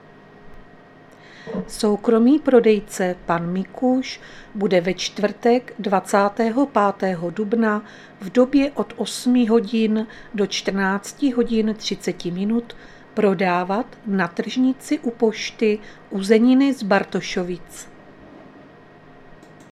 Záznam hlášení místního rozhlasu 24.4.2024